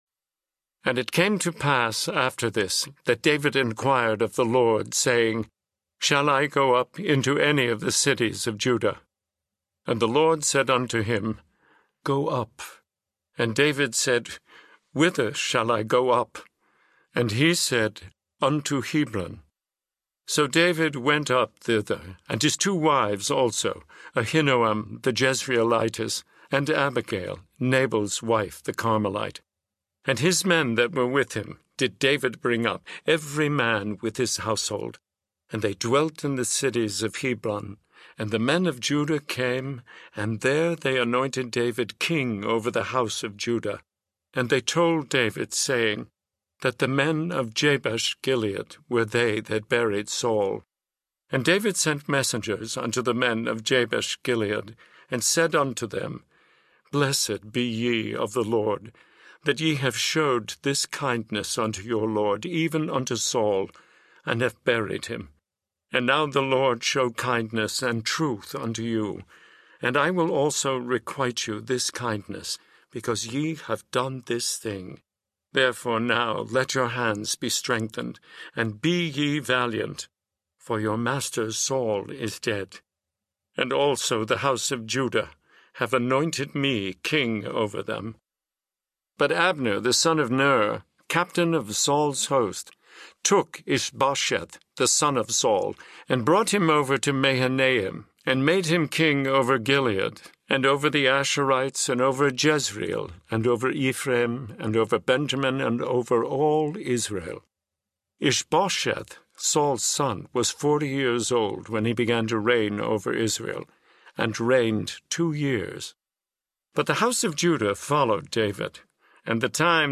The King James Version (KJV) of 2 Samuel in a pure voice audio format. Clear and uncluttered, this Bible speaks plainly, making it easier for you to enter into God’s Word. Actor and director David Birney speaks new life to the cherished and revered translation.
Narrator
2.3 Hrs. – Unabridged